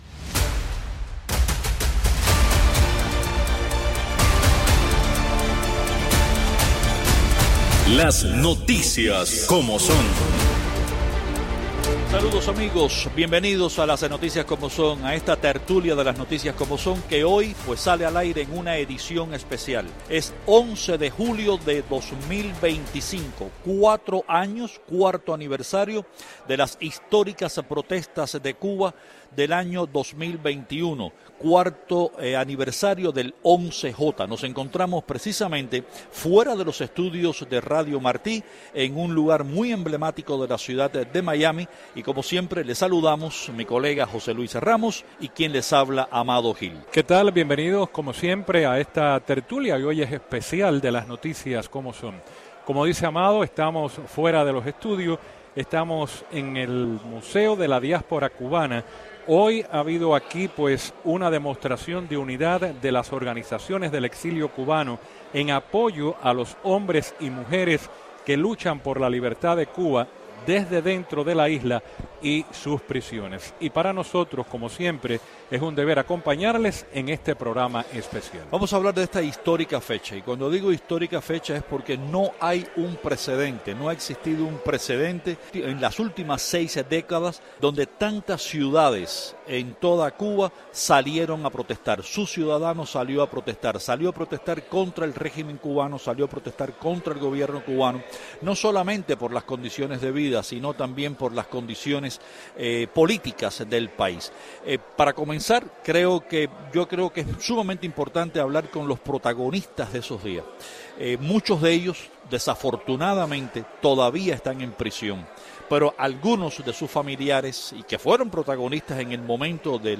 Martí Noticias transmitió este viernes desde el Museo Americano de la Diáspora Cubana durante un evento para recordar las masivas manifestaciones del 11 de julio del 2021.